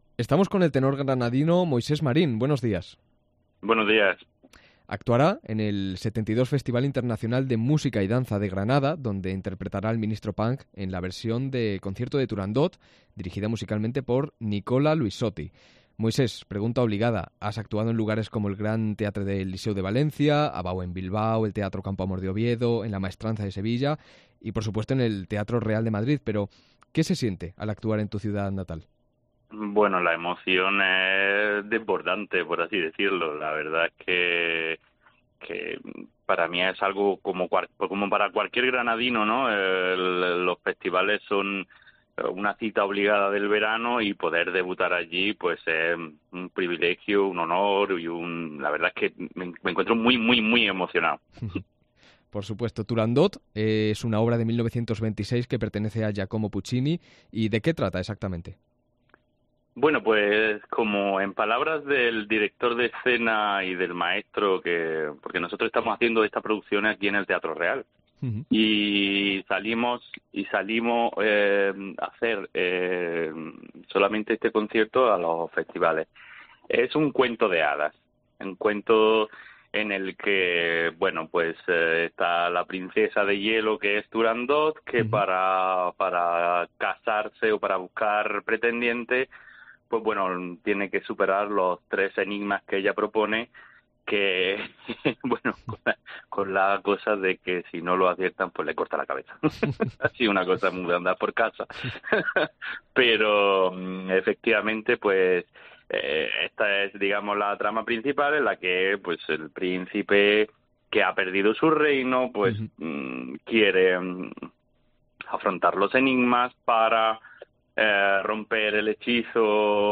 Entrevista al tenor granadino